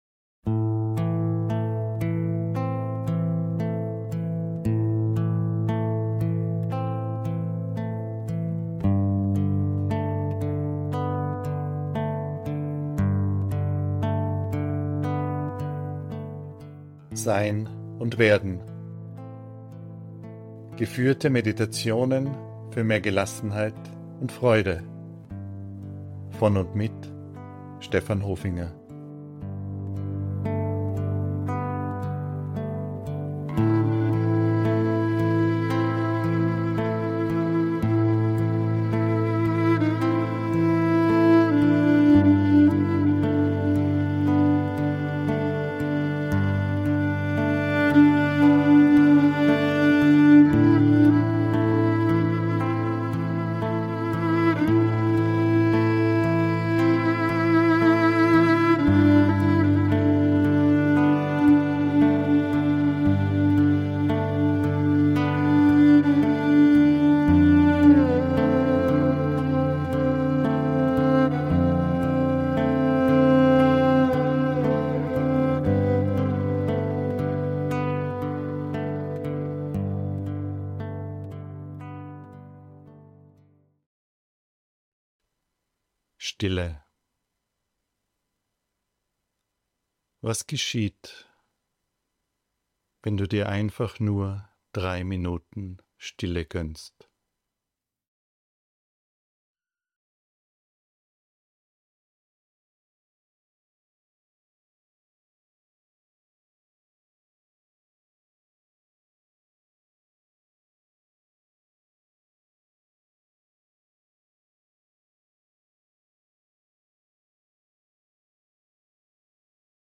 Meditationen für mehr Gelassenheit und Freude